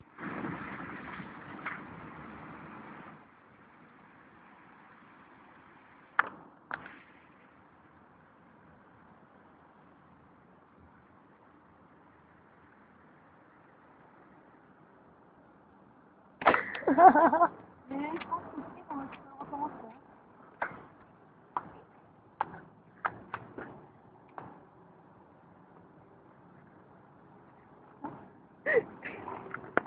Bruits d'une personne qui rigole et voitures